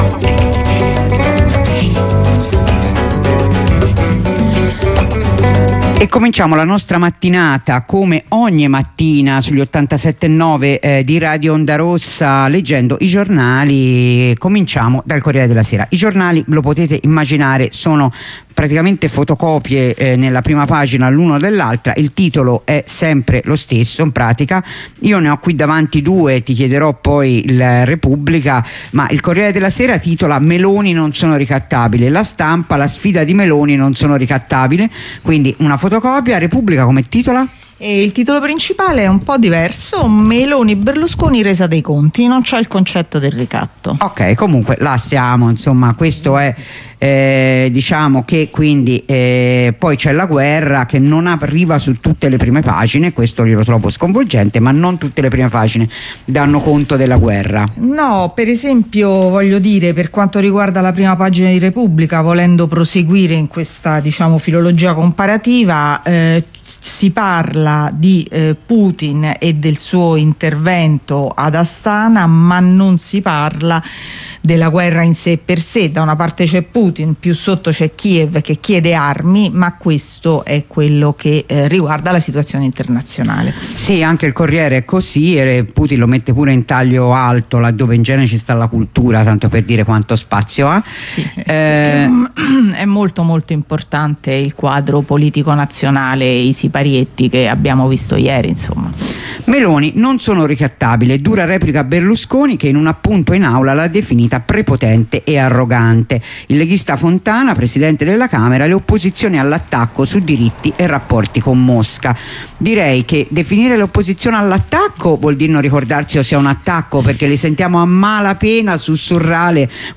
La rassegna stampa di Radio Onda Rossa